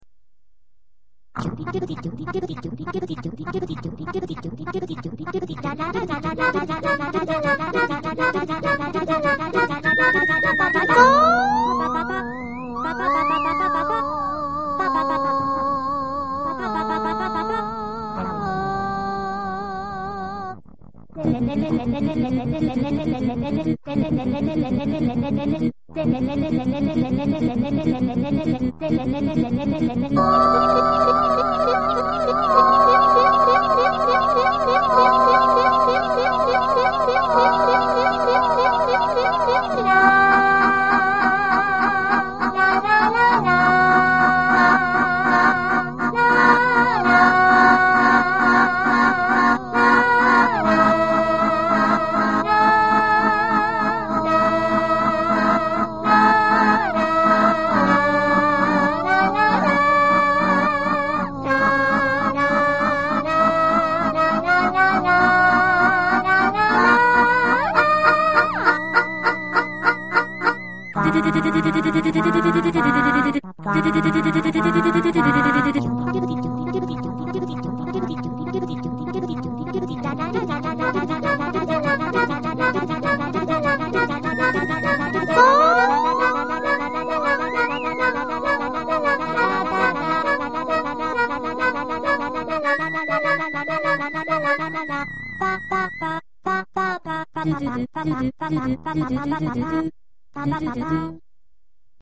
が、ドラムを入れてなかったりいろいろ失敗…もとい挫折しましたｗ